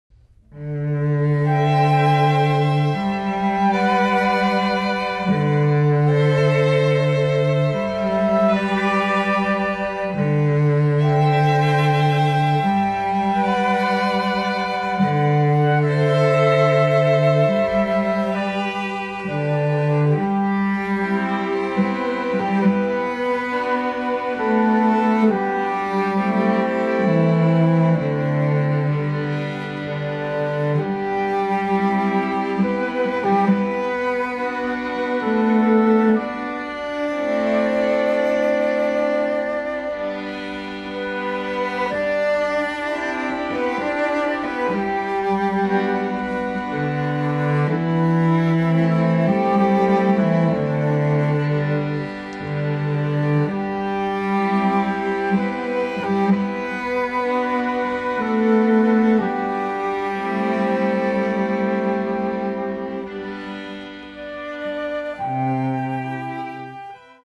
(Flute, Violin, Viola and Cello)
(Two Violins, Viola and Cello)